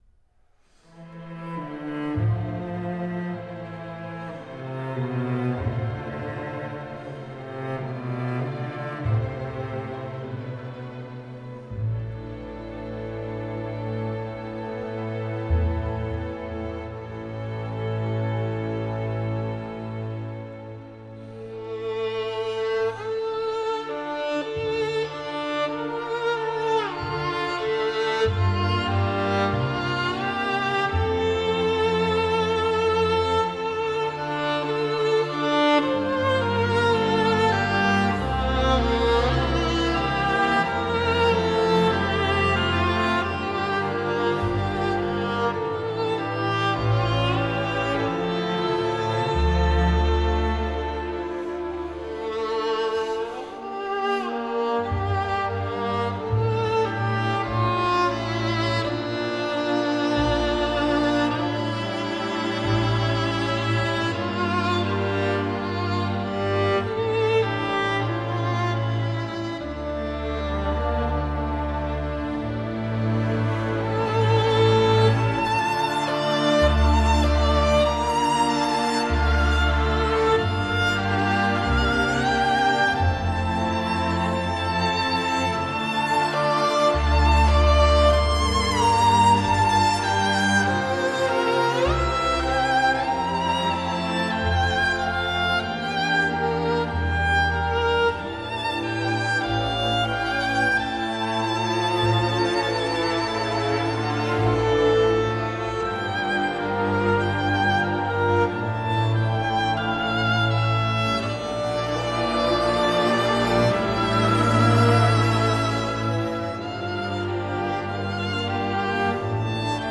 Genre: Classical, Violin